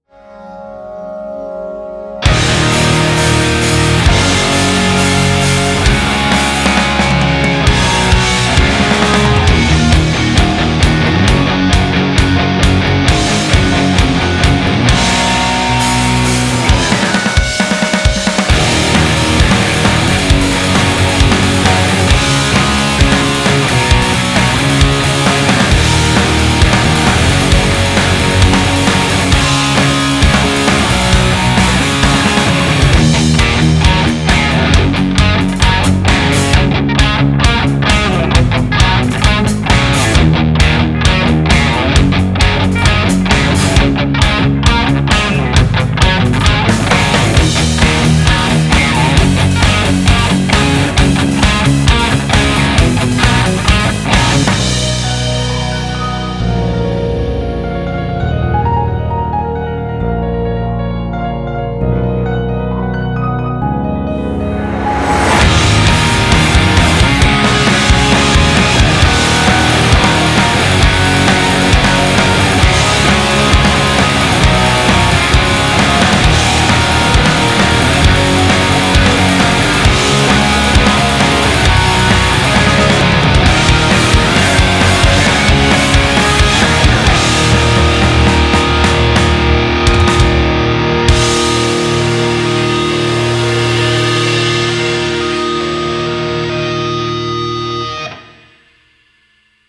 ■OFF VOCAL